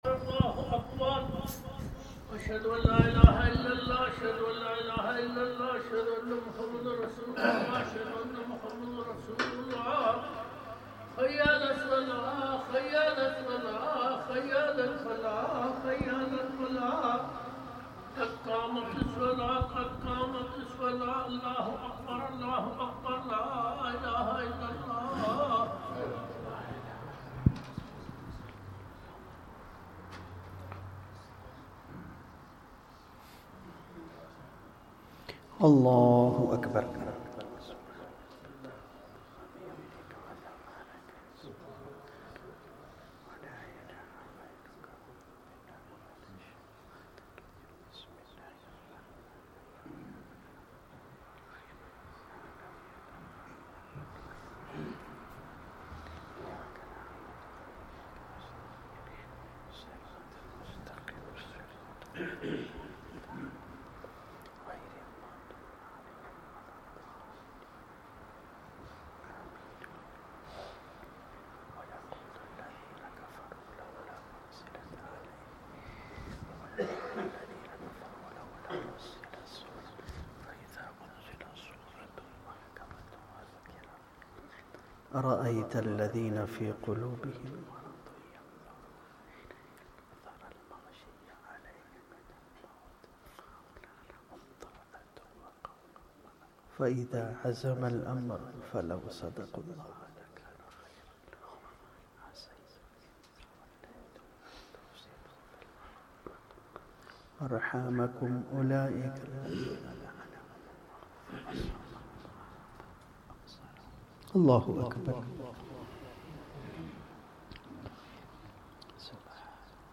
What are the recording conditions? Wednesday Quran Study Circle